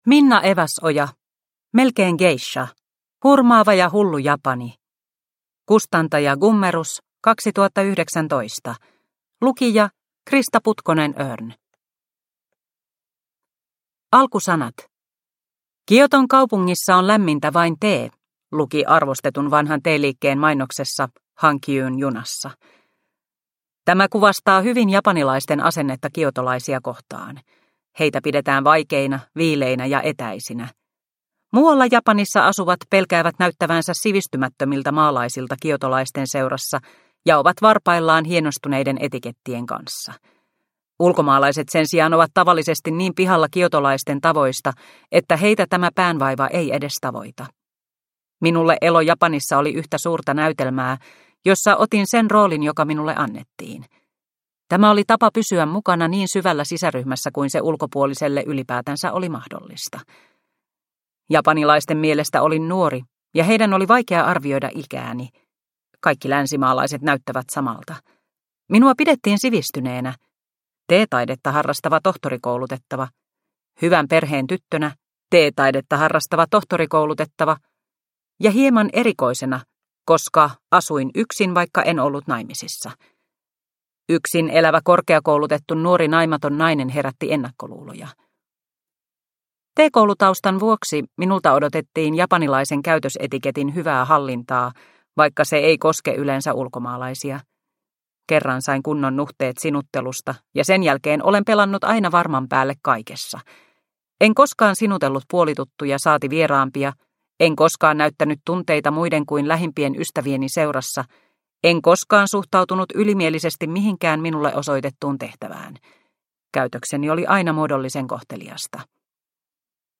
Melkein geisha – Ljudbok – Laddas ner